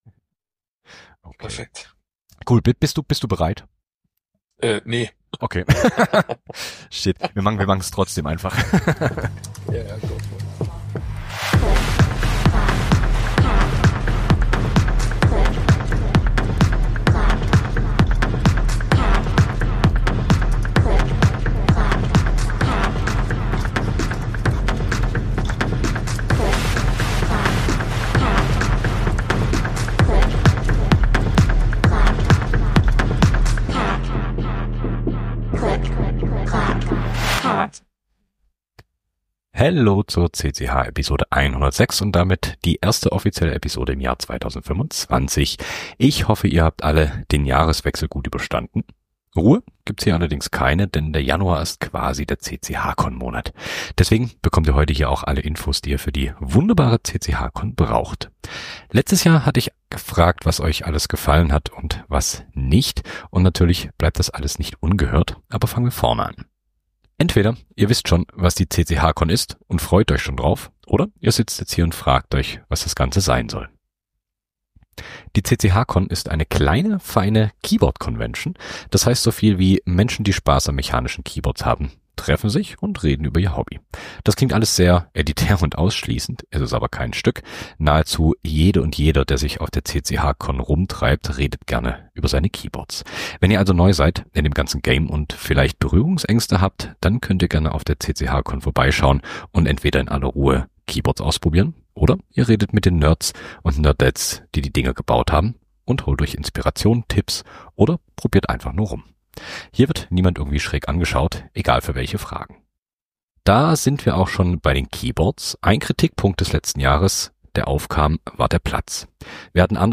Ich habe die Speaker*innen vors Mikro gezerrt und sie über ihre Talks reden lassen.